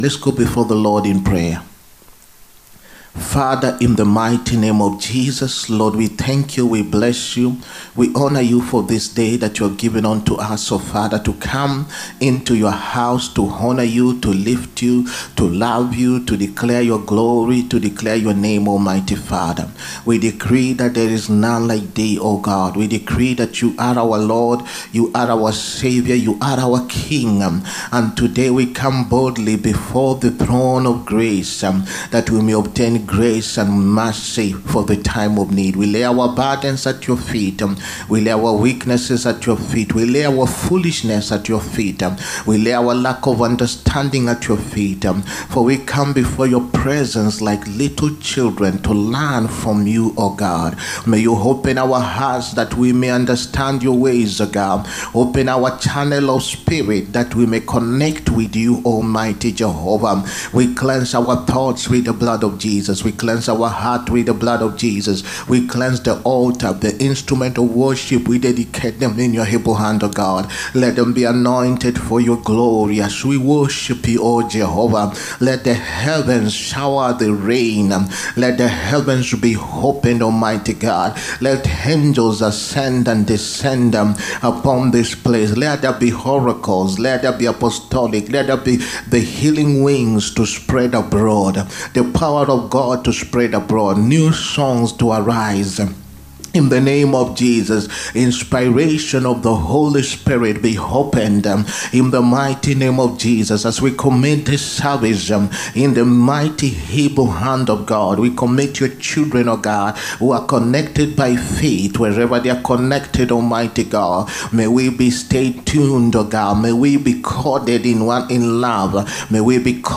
SUNDAY BLESSINGS SERVICE. WHY WE NEED BLESSINGS. 27TH APRIL 2025.